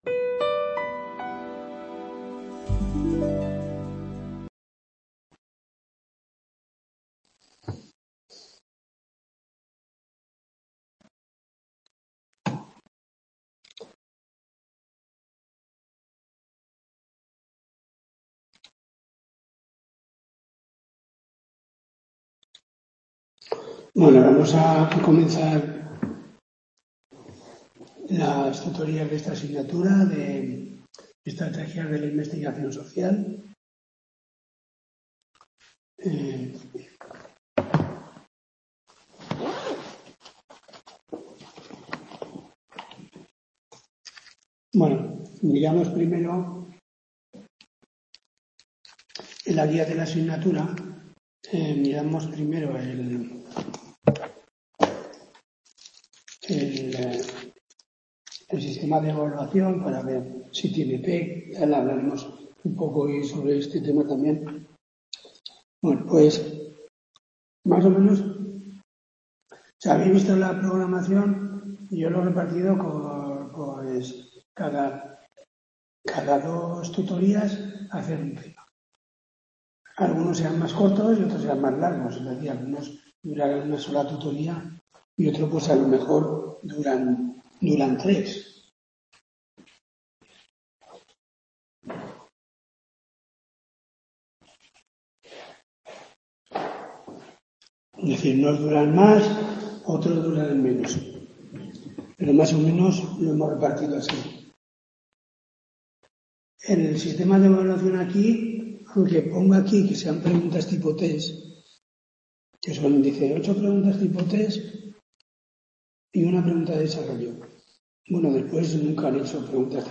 TUTORÍA DE 12/02/2025